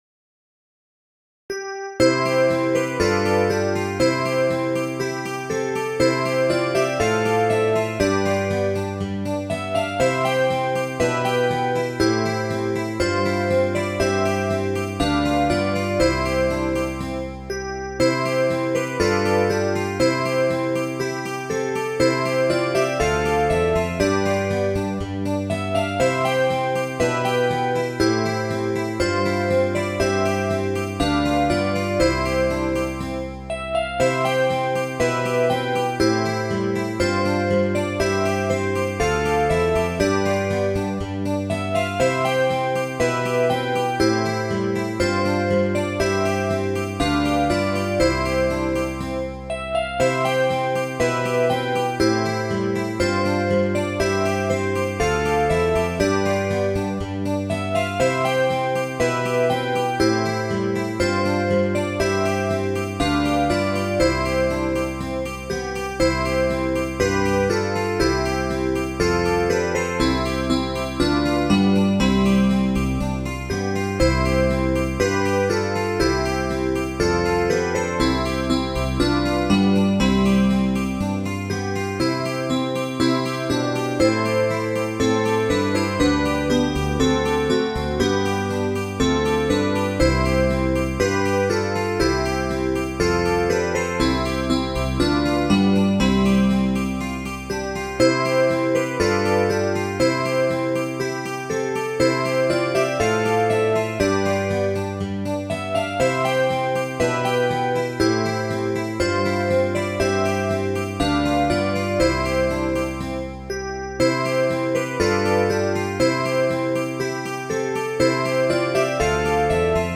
maidbell.mid.ogg